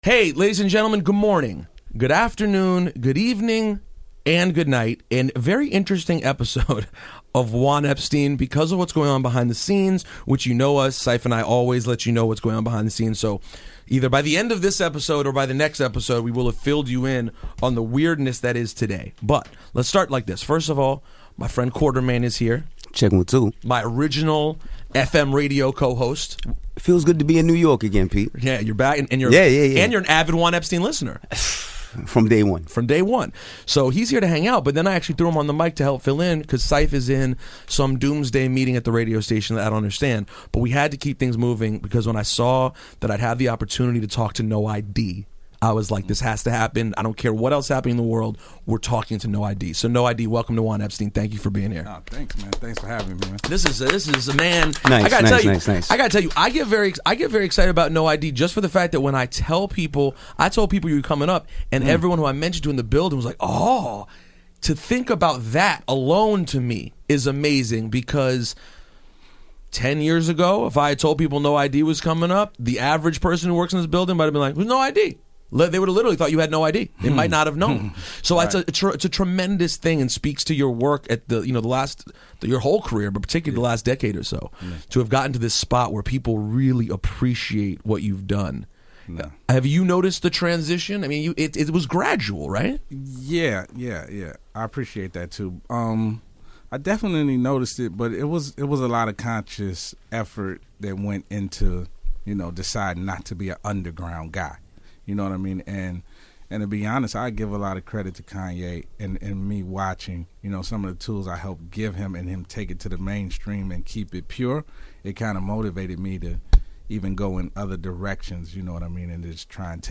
For starters, No I.D. is an amazing interview and his story is incredibly inspirational.